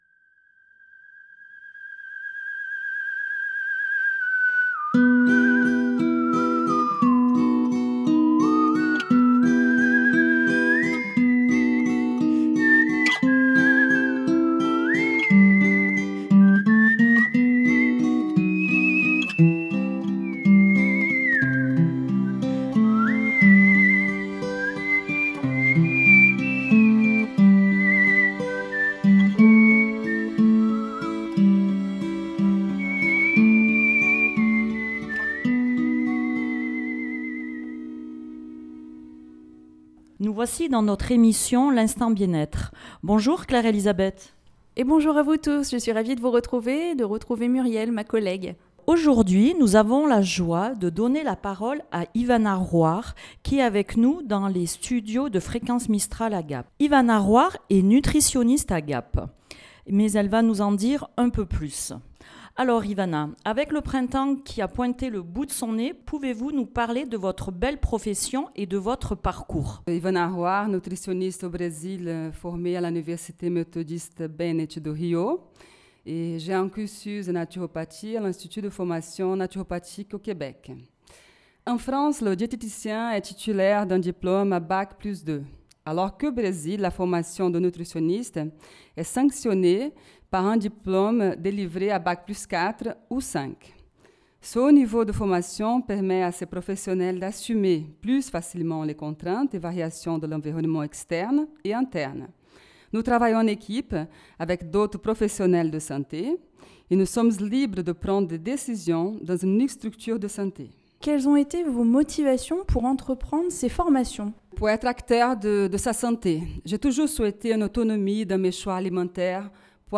avec son bel accent